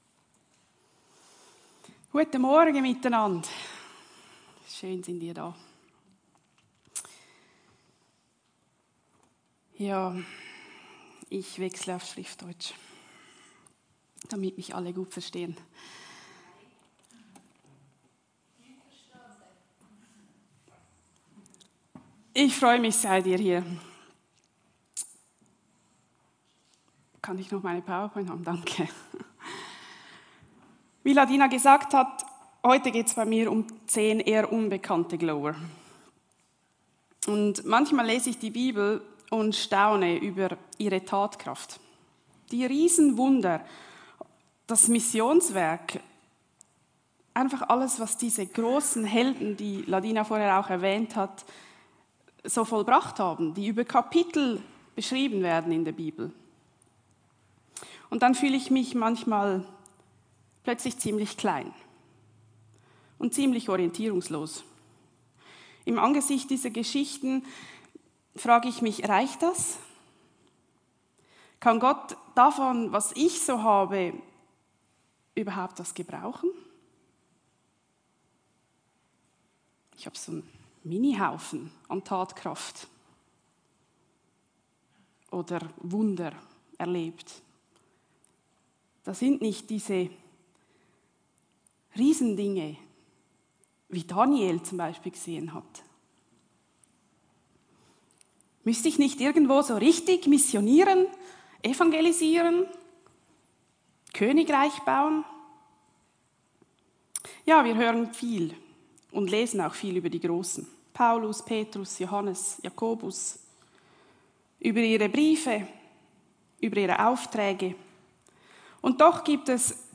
Predigt-26.04.mp3